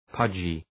{‘pʌdʒı}